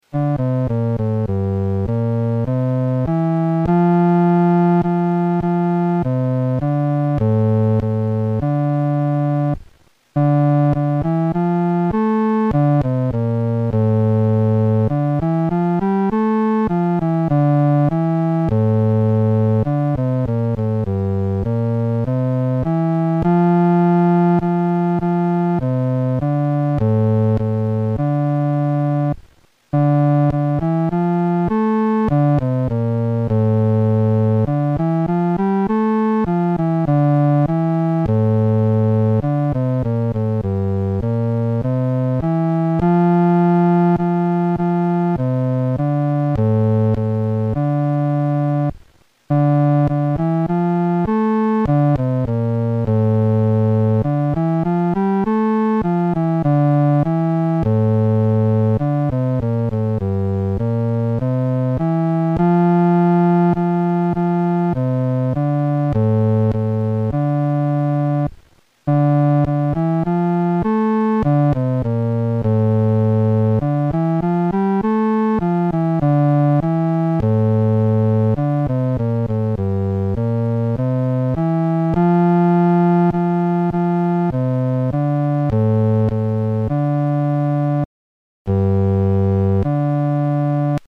伴奏
男低
这首诗歌宜用不太慢的中速弹唱。